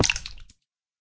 land_hit3.ogg